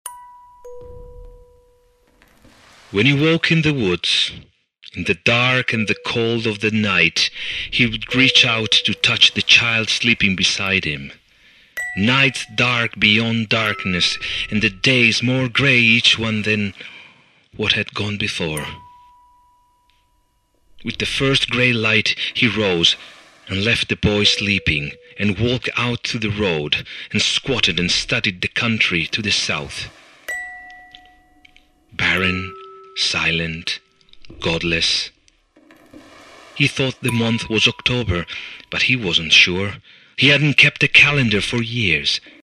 Liquid and dilated electroacoustic music
dense rarefazioni ambient